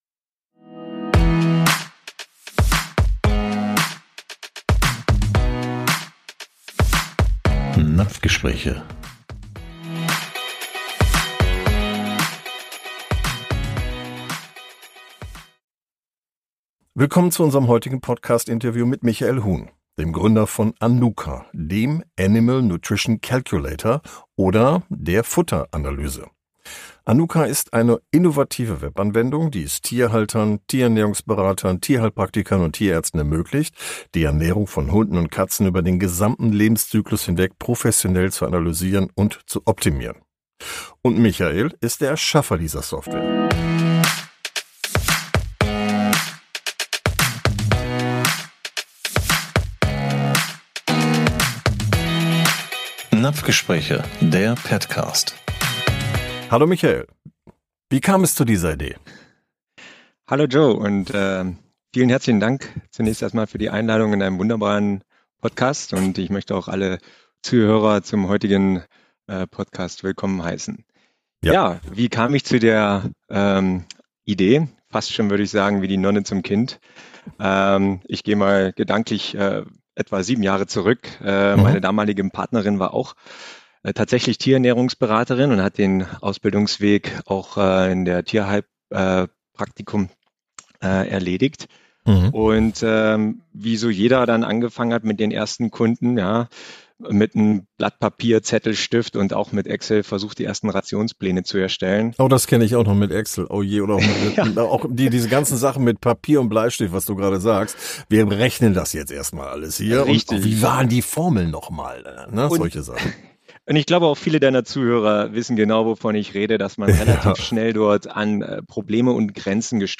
Unser heutiger Podcast-Interviewgast ist